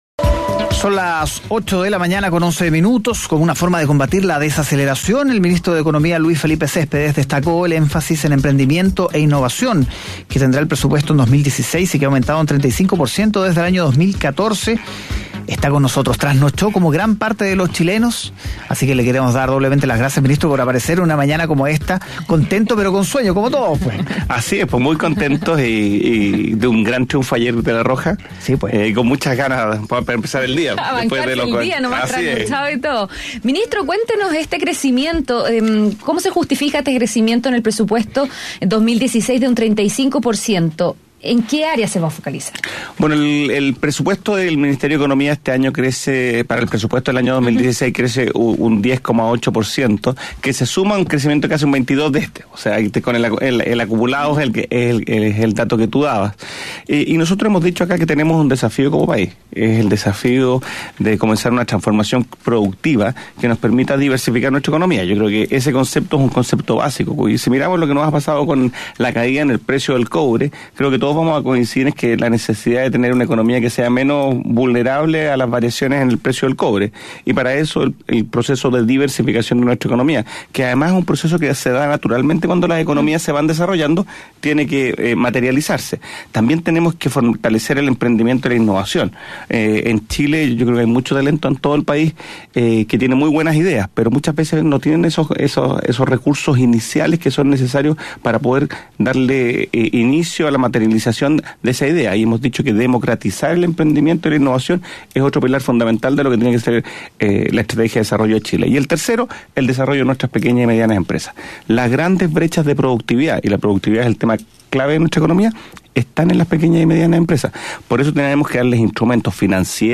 Escucha la entrevista completa realizada en Mañana Será Otro Día: